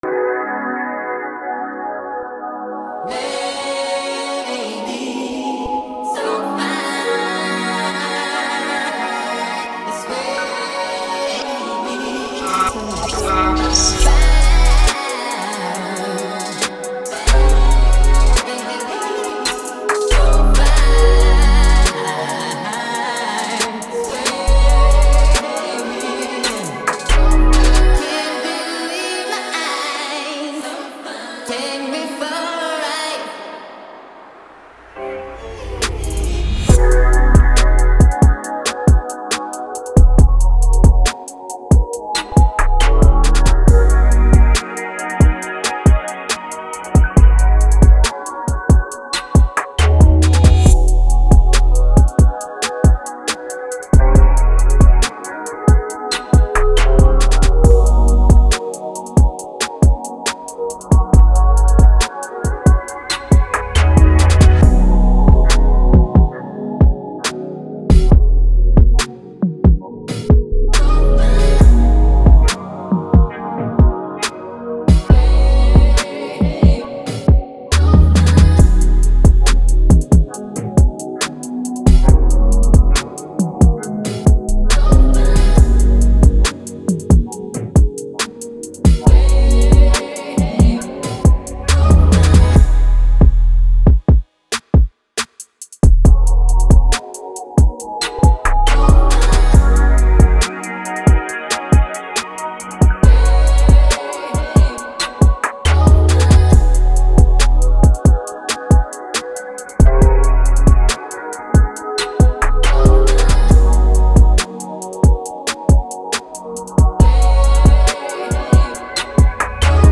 BPM: 138 Key: G♯ minor